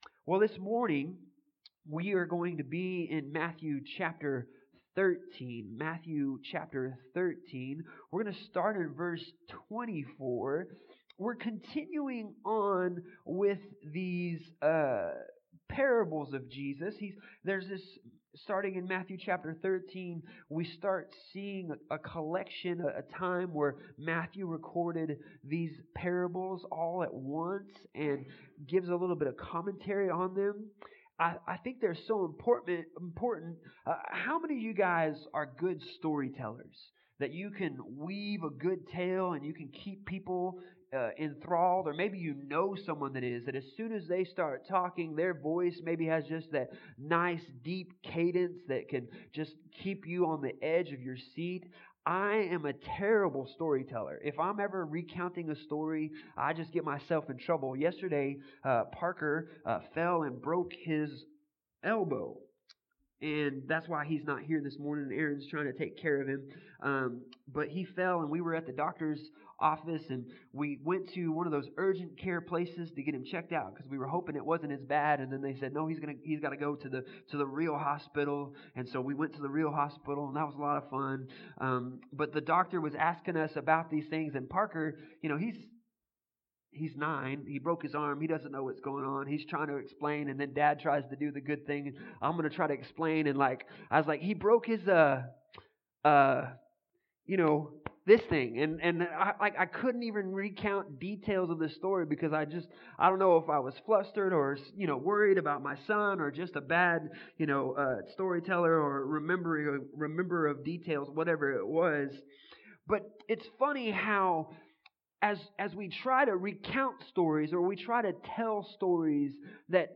Passage: Matthew 13:24-43 Service Type: Sunday Morning